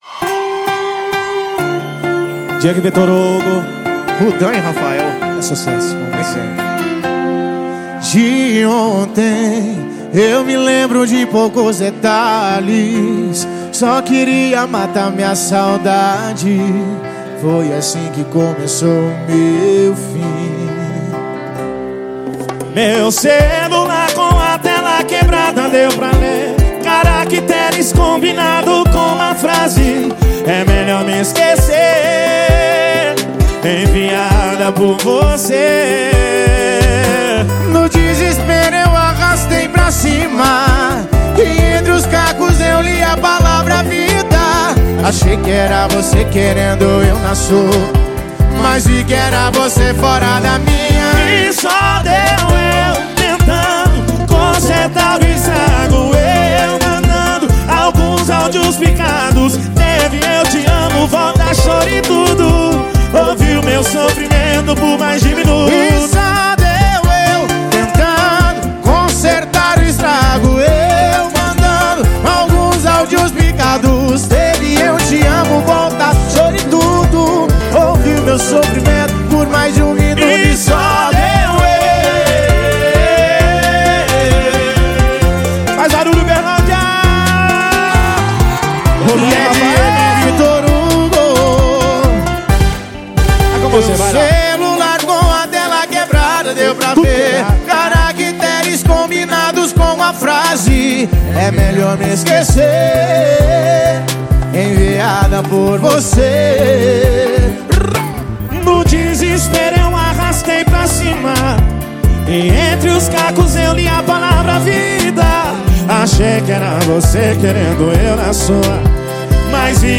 Música – ouvir online e acessar o arquivo